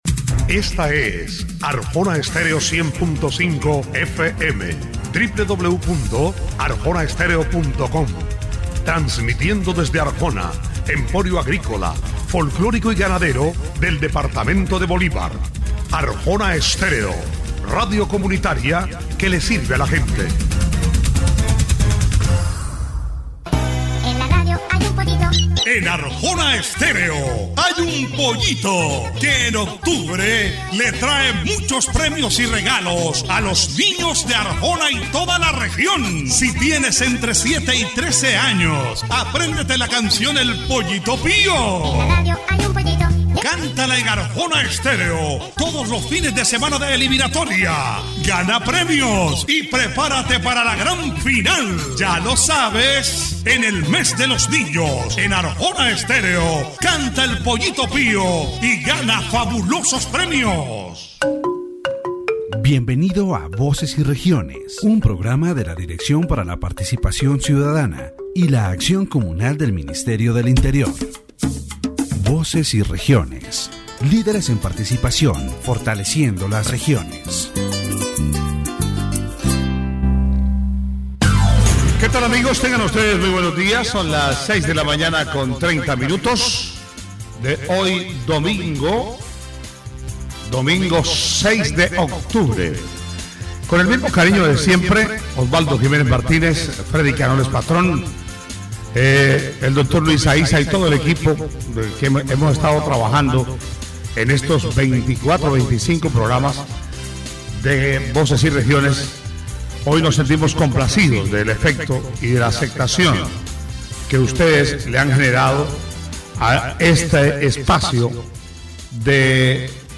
The "Voces y Regiones" program, broadcast on Arjona Estéreo and supported by the Ministry of the Interior, addressed the topic of justice in Colombia, highlighting the challenges within the judicial system and its historical evolution.